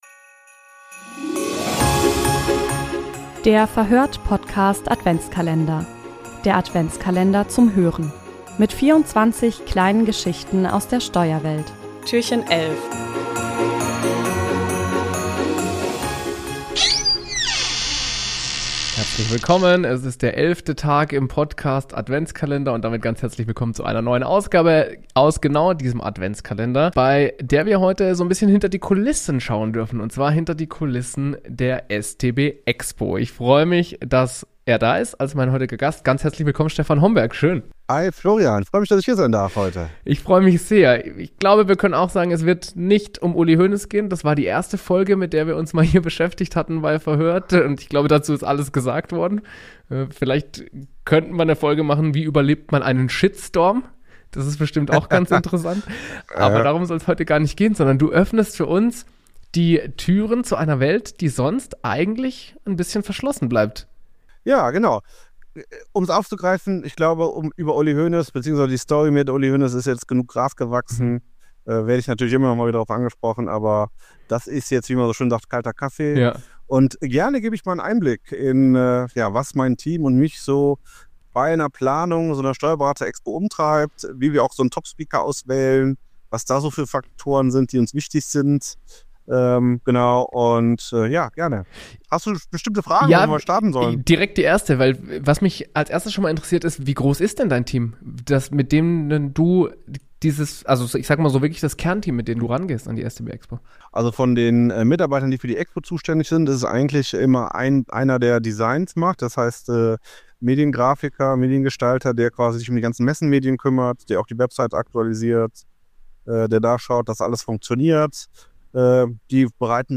Im Verhör(t) Podcast-Adventskalender erzählen Steuerexpertinnen und Steuerexperten Geschichten und Anekdoten aus ihrem Alltag.